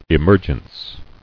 [e·mer·gence]